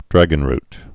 (drăgən-rt, -rt)